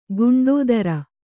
♪ guṇḍōdara